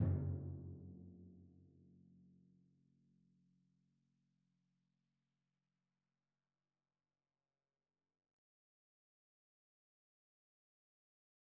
Timpani1_Hit_v3_rr2_Sum.wav